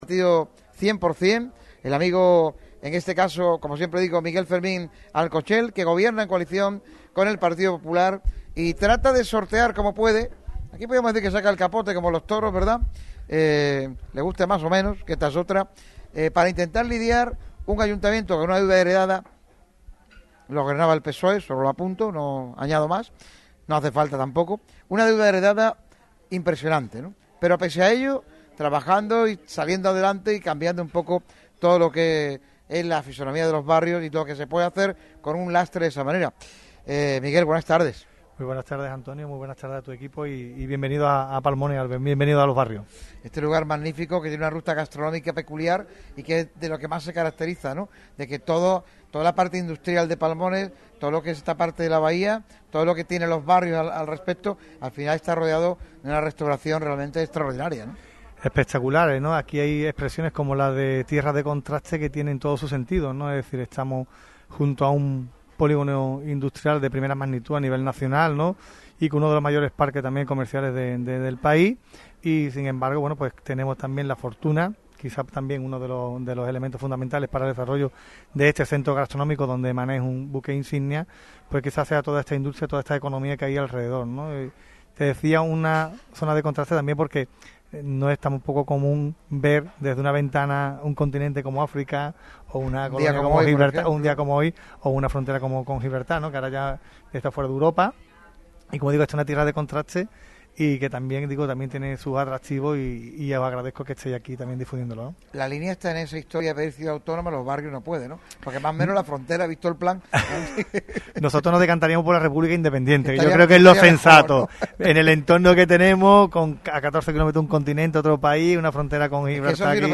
Miguel Fermín, alcalde de Los Barrios, se pasa por el micrófono rojo de Radio Marca Málaga, en un programa especial en Casa Mané, rincón por excelencia en Palmones.